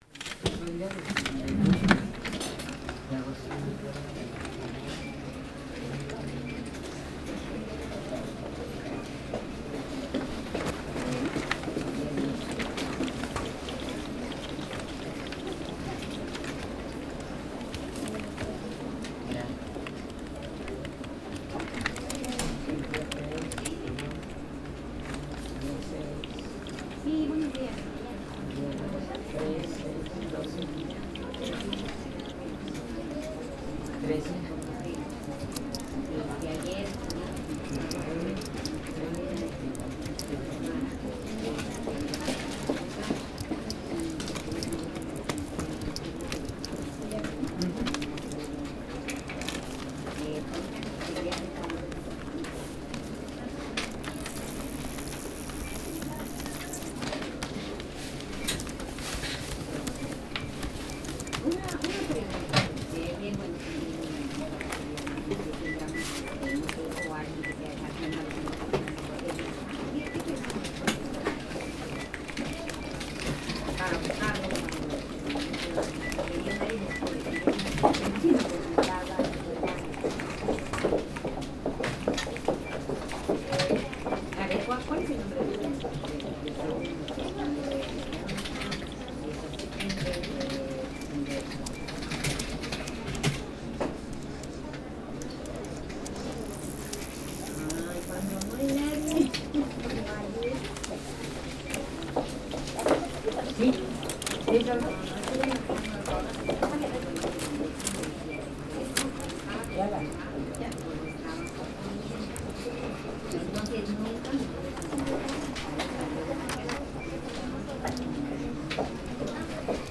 ASMR - Мытье посуды, уборка на кухни 18:32
Приятные звуки мытья посуды для ваших мурашек и крепкого сна. Эти звуки помогут расслабиться и уснуть.